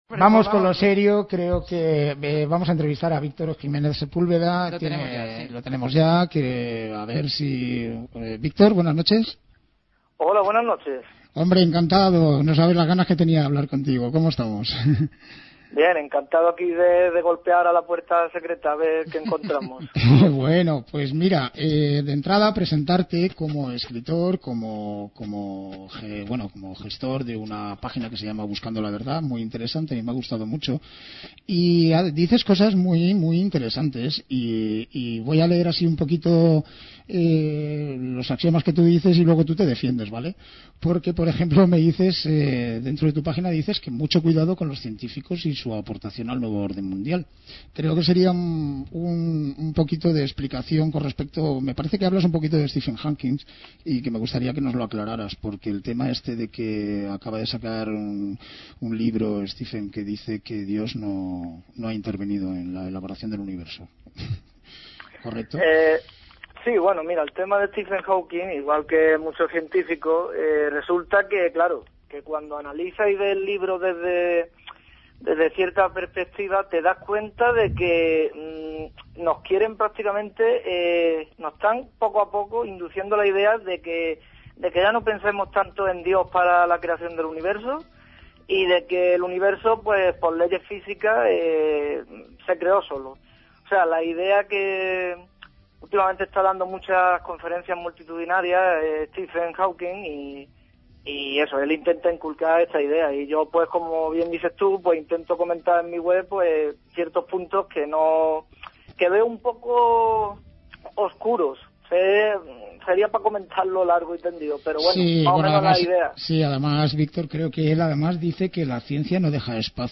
ENTREVISTA DE RADIO CONCEDIDA EN EL PROGRAMA "LA PUERTA SECRETA" DE RADIO-LIBERTAD-FM